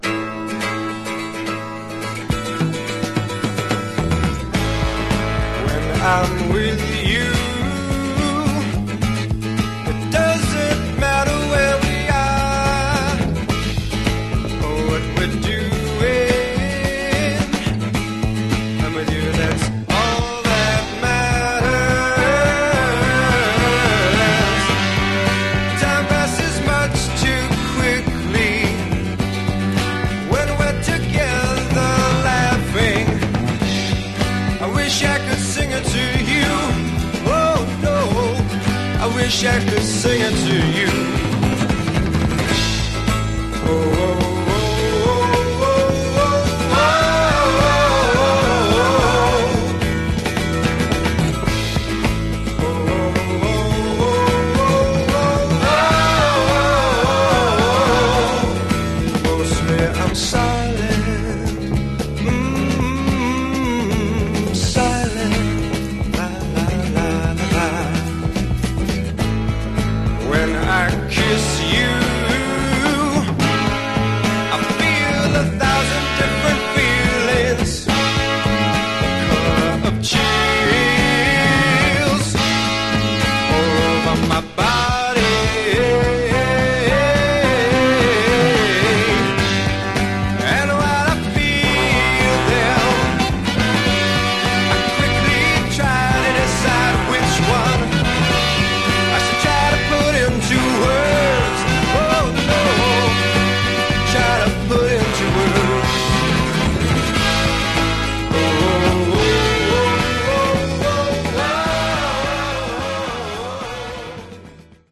Genre: Horn Rock